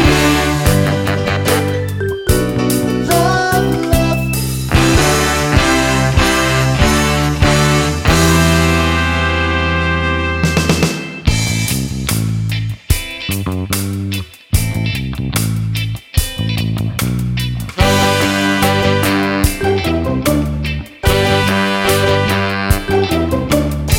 no Backing Vocals Ska 3:18 Buy £1.50